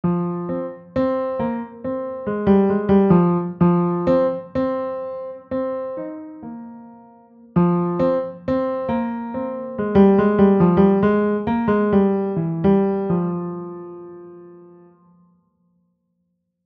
Pianino
Wykorzystywana jest technika fortepianowa, jednak pianino ma mniejsze możliwości brzmieniowe.
Dźwięki instrumentów są brzmieniem orientacyjnym, wygenerowanym w programach:
Kontakt Native Instruments (głównie Factory Library oraz inne biblioteki) oraz Garritan (Aria Player).
Pianino.mp3